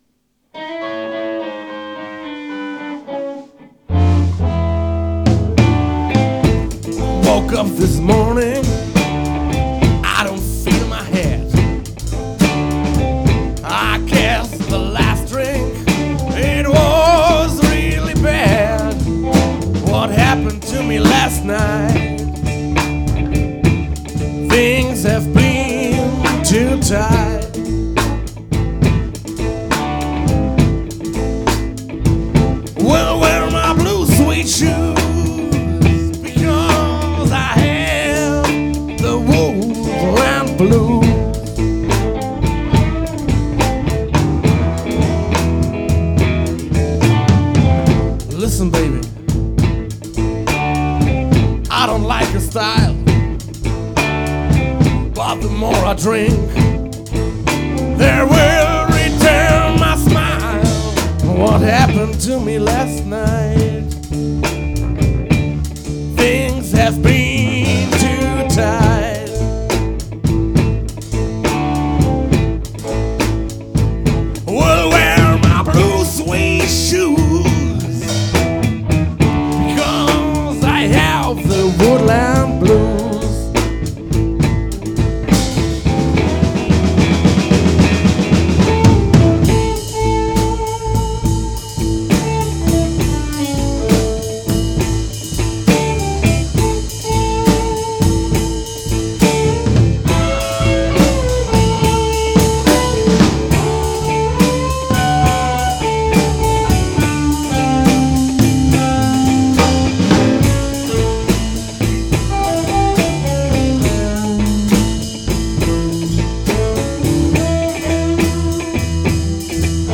Rock & Roll Band!
Memories of Rock & Roll, Soul, Rhythm Blues.
Ehrliche, handgemachte Rock- Musik
Bass
Drums